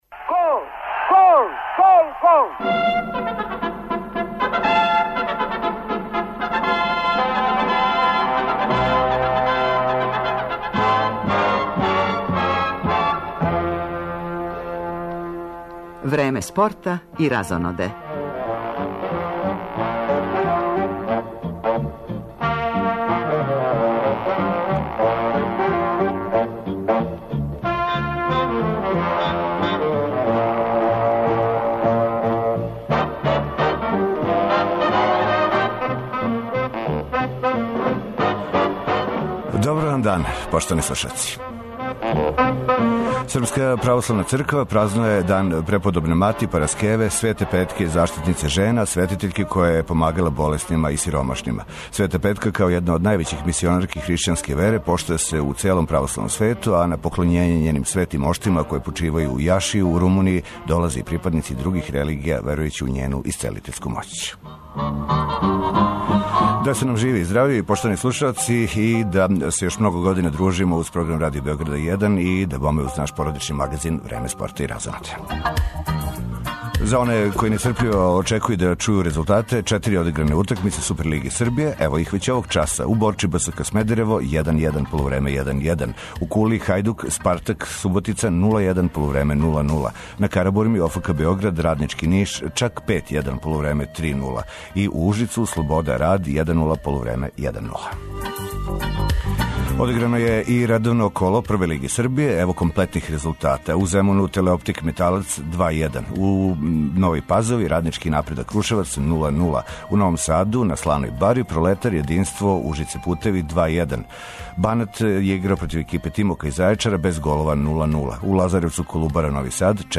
Током емисије чућемо извештаје са фудбалских утакмице Супер лиге Србије, резултате наше Прве лиге, пратићемо и кретања резултата на утакмицама важнијих европских шампионата. Гост у студију је Мики Јевремовић, поводом концерта у Дому синдиката, којим обележава пола века бављења музиком.